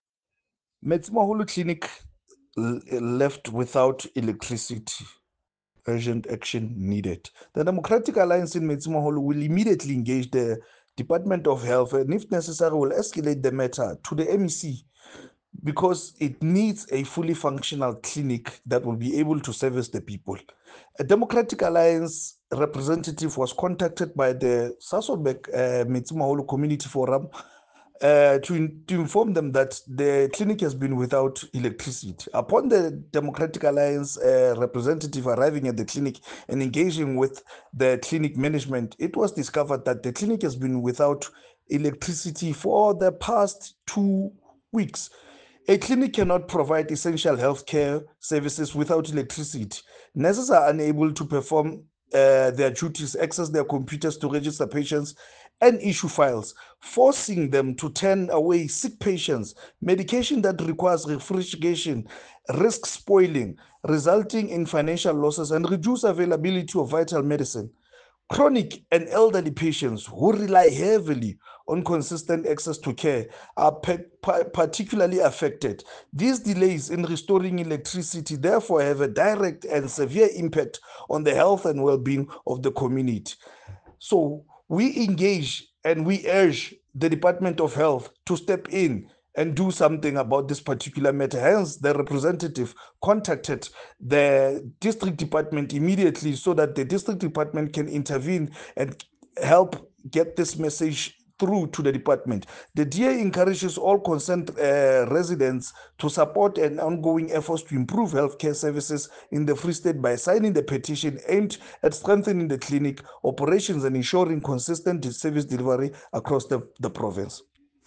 Sesotho soundbites by Cllr Thabang Rankoe and
Sasolburg-Clinic-English.mp3